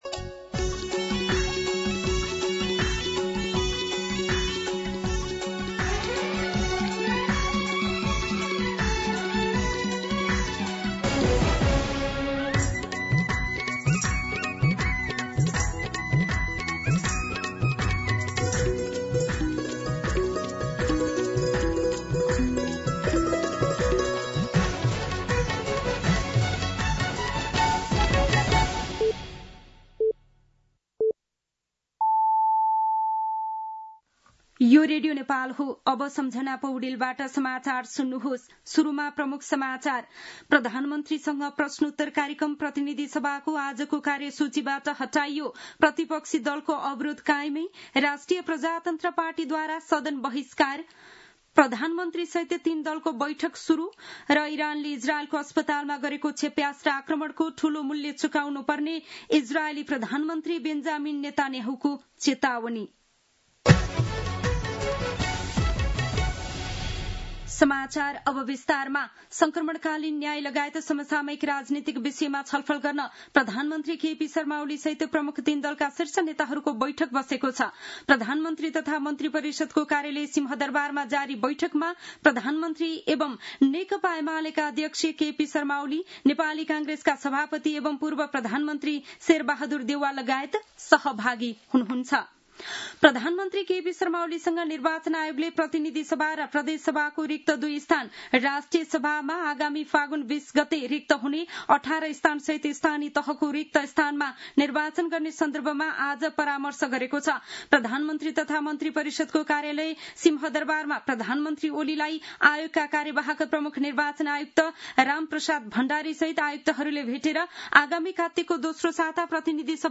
दिउँसो ३ बजेको नेपाली समाचार : ६ असार , २०८२